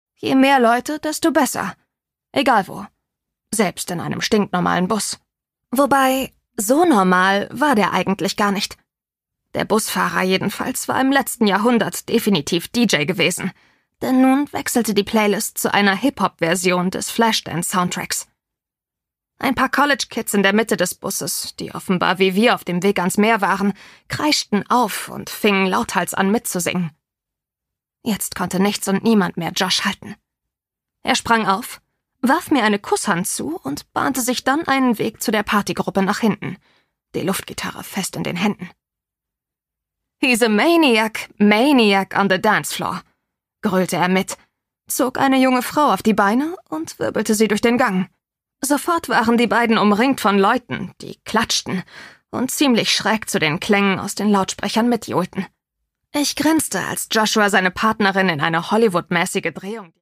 Produkttyp: Hörbuch-Download
MP3 Hörbuch-Download